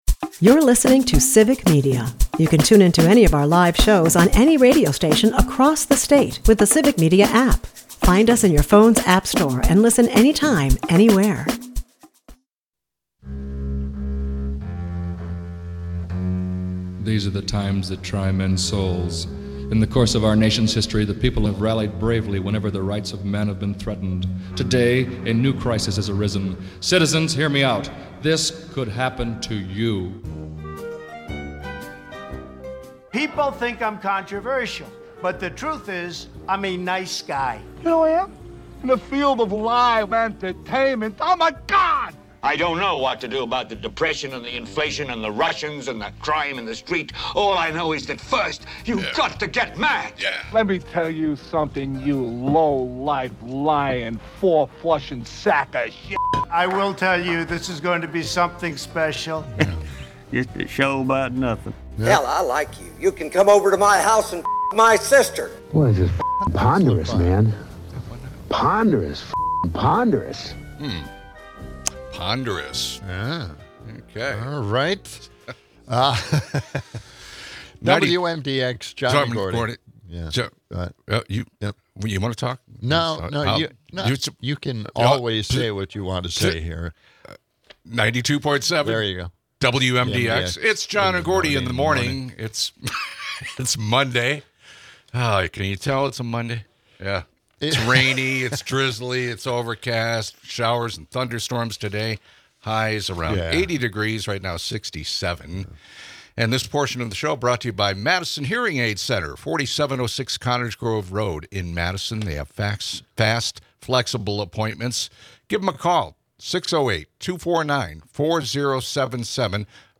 Plus, whispers of Fox News' potential leftward shift stir the media world. All this amidst Wisconsin's soggy weather forecast, punctuated by listener engagement and lively banter.